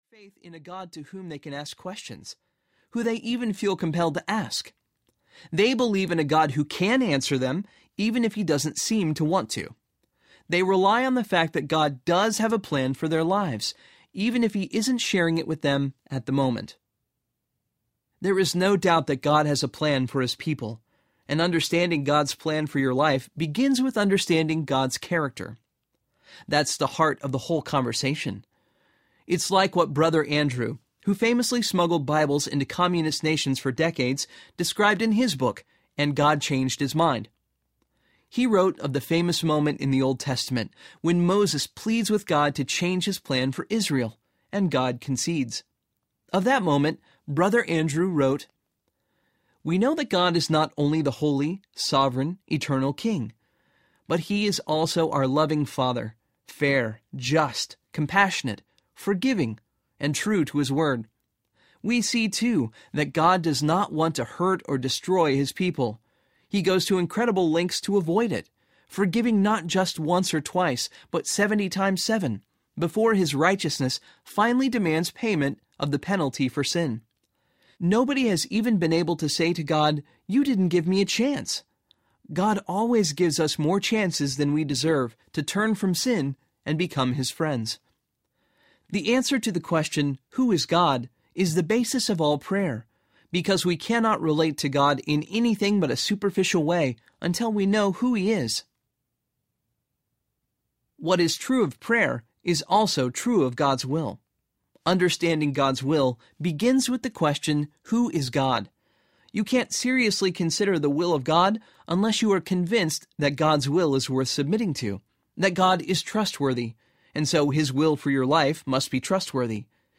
What Am I Supposed to Do With My Life? Audiobook
Narrator